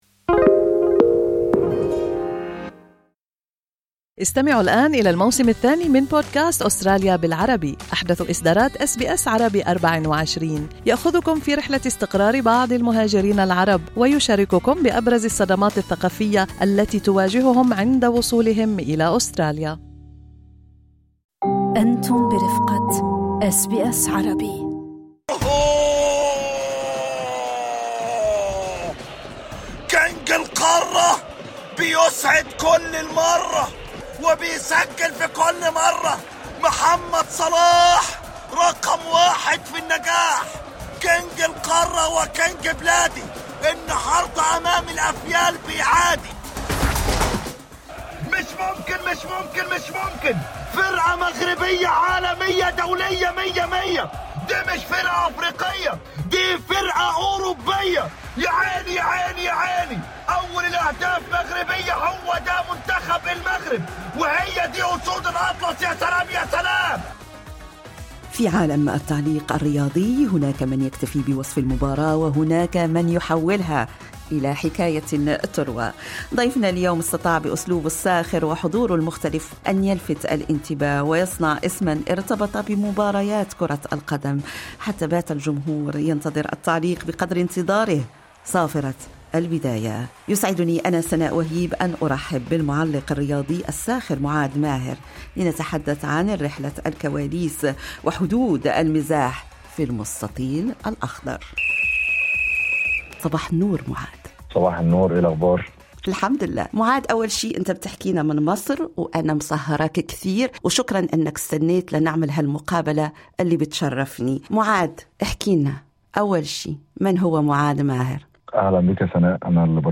Sport Commentator